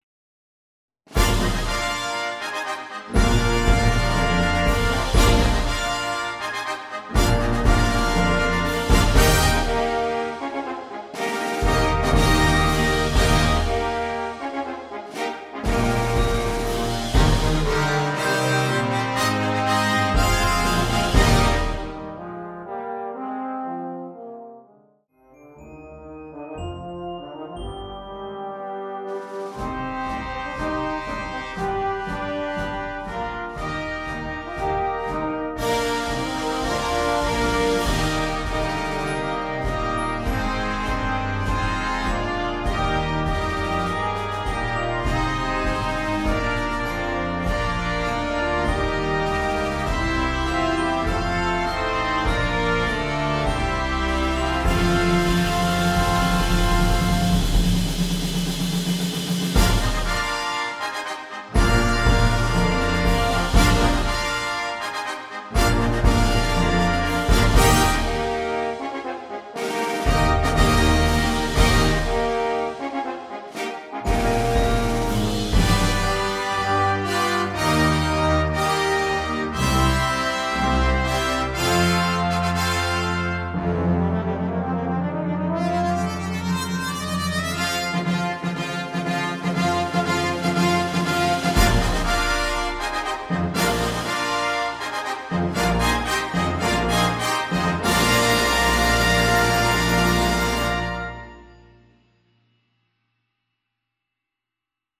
BRASS AUDIO DEMO